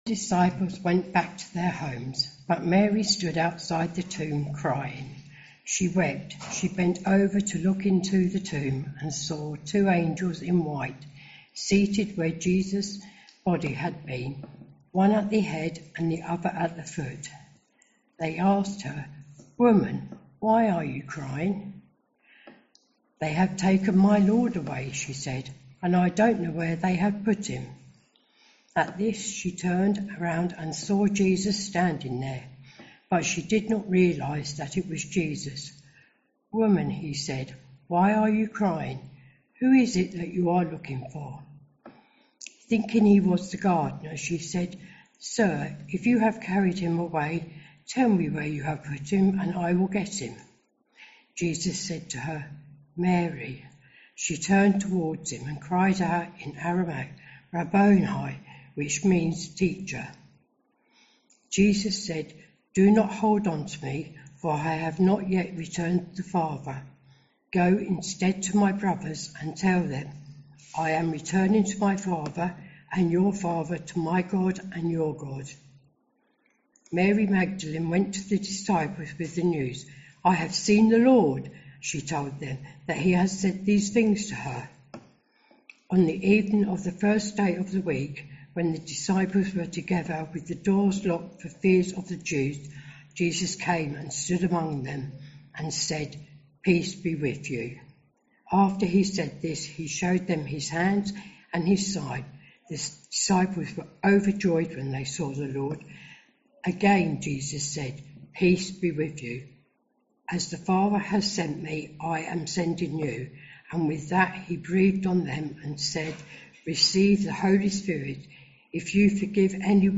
Sermons - Swanfield Chapel